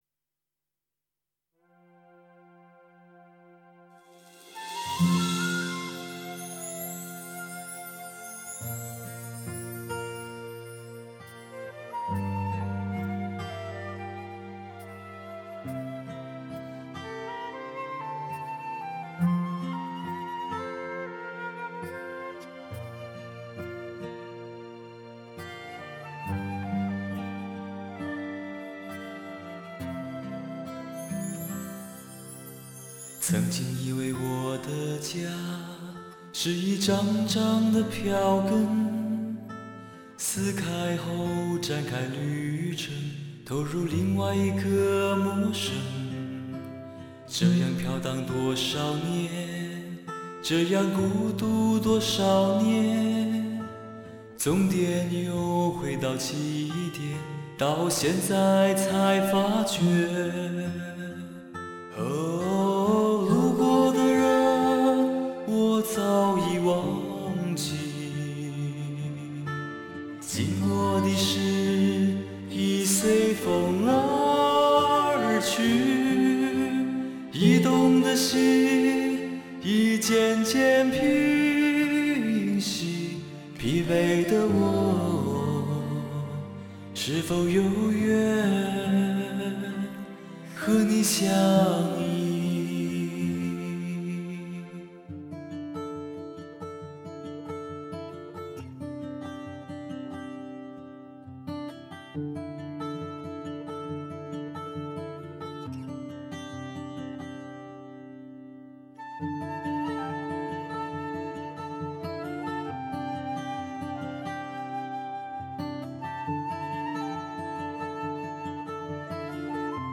歌手那充满阳光魅力，充满感人磁性的嗓子，很容易让你听完又听。